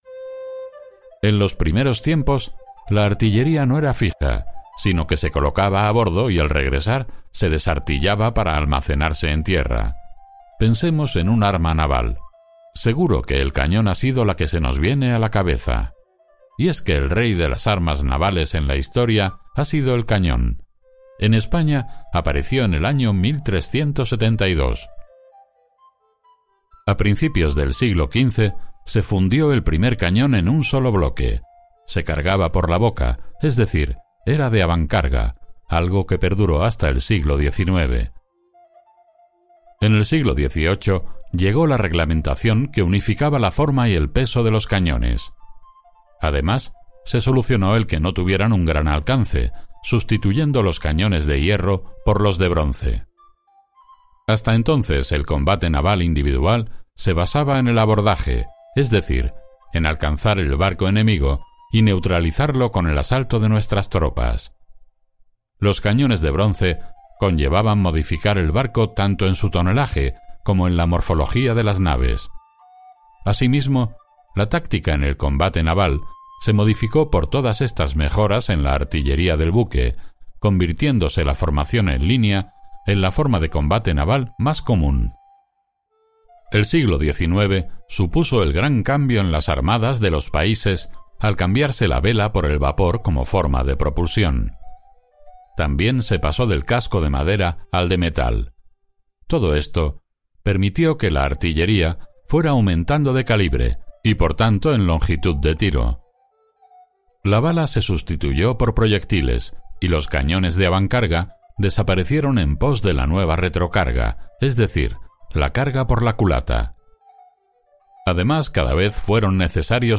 audioguia_Museo_Naval_San_Fernando_ES_13.mp3